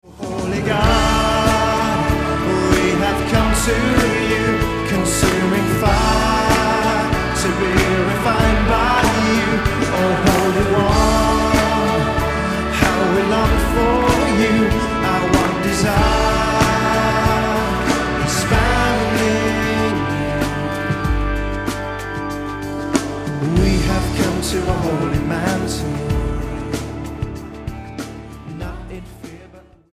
STYLE: Pop
catchy, mid tempo praise song